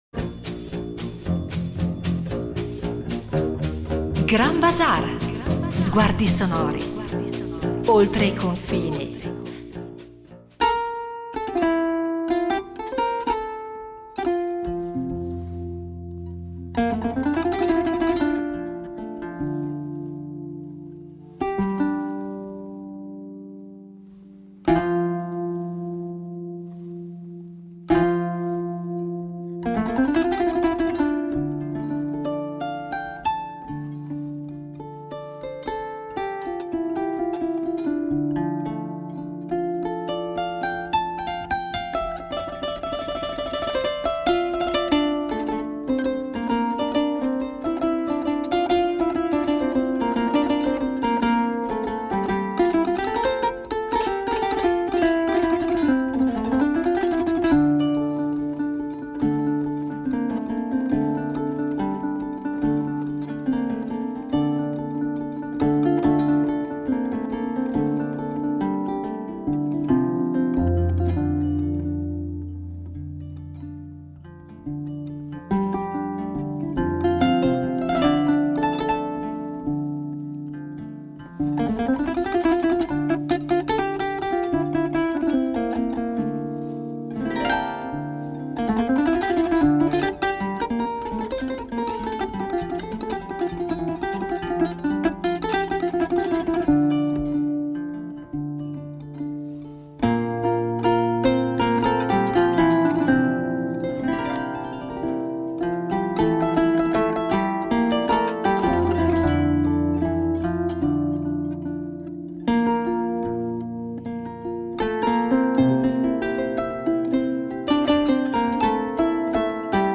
Attraverso racconti, esempi sonori e riflessioni, esploreremo le funzioni sociali del griot, la sua formazione, le tecniche di narrazione e le trasformazioni di questa figura nell’Africa contemporanea e nella diaspora. Ascolteremo brani di griot storici e contemporanei, scoprendo come questa forma d’arte orale – trasmessa per via ereditaria e spesso riservata a caste specializzate – sia tutt’altro che immobile: capace di reinventarsi, di parlare di attualità, di mescolare tradizione e modernità.